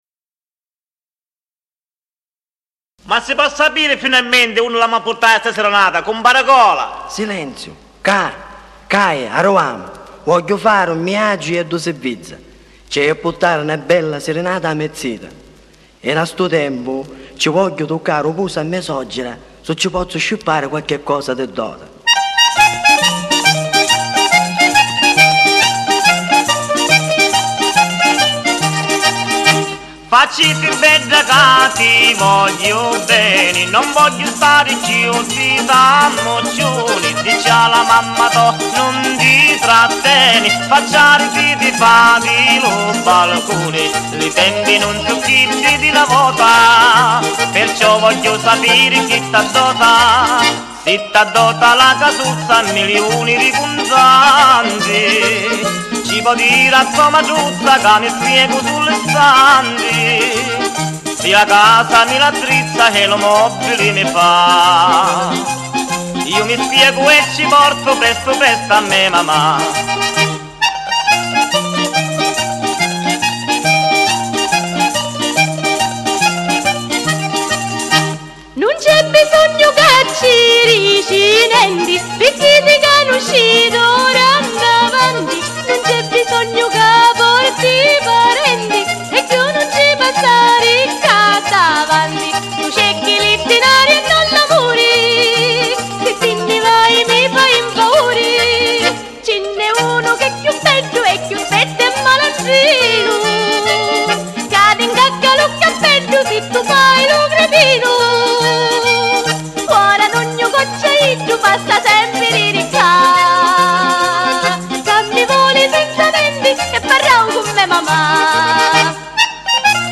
COMPLESSO CARATTERISTICO SICILIANO
ZUFOLO
FISARMONICA